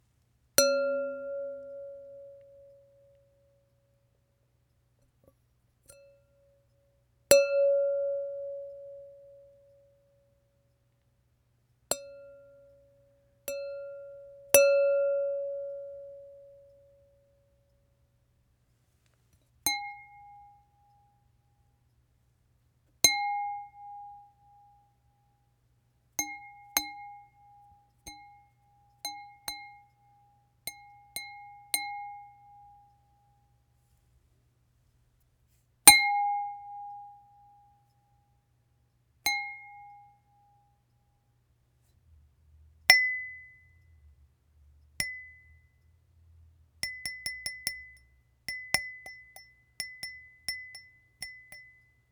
Wine glass tinkles
clink ding glass marimba music ping ting tone sound effect free sound royalty free Music